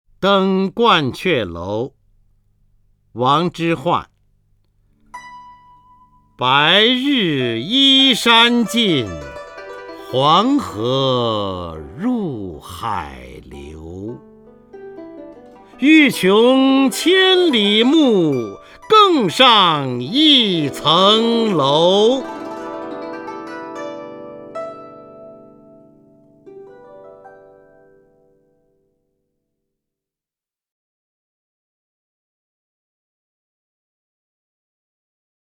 方明朗诵：《登鹳雀楼》(（唐）王之涣) （唐）王之涣 名家朗诵欣赏方明 语文PLUS